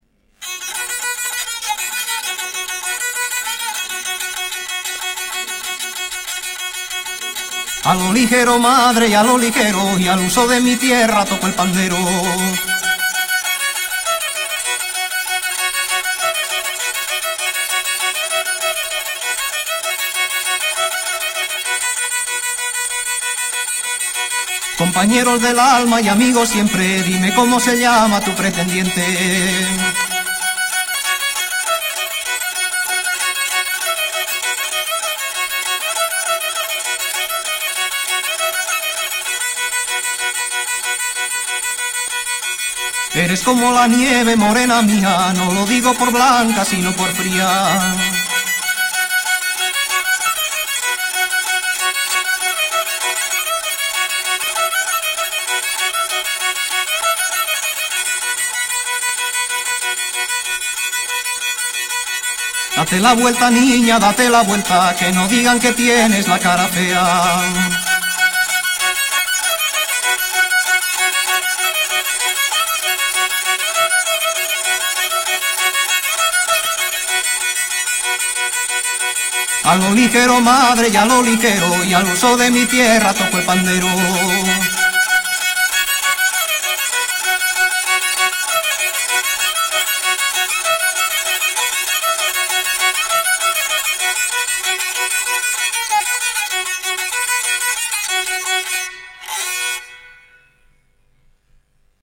Vinilo
Rabel, zanfona y sonajas